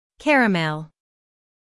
IPA: /ˈkær.ə.mɛl/.
How to Pronounce Caramel
Syllables: CAR · a · mel
caramel-us.mp3